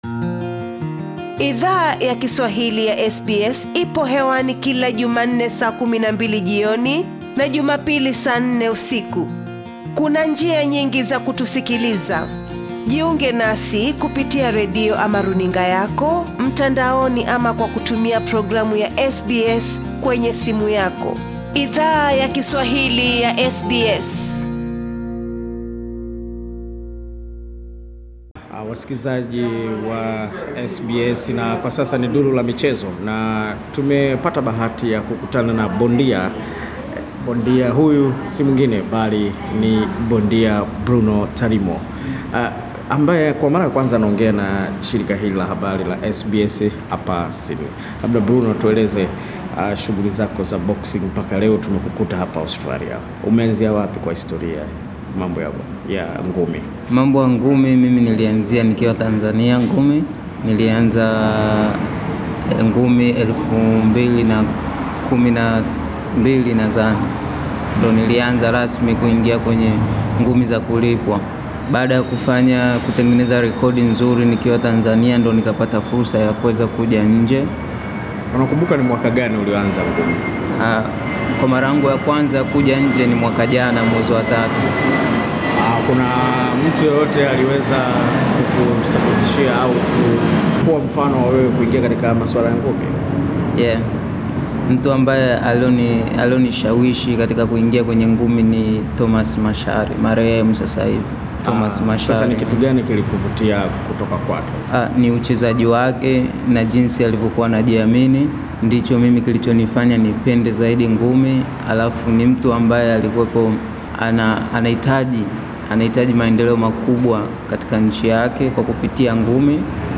Image Katika moja ya mahojiano machache ambayo amefanya kufikia sasa